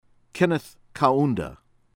KASONDE, EMMANUEL ee-MAN-yoo-ehl    kah-SOHN-deh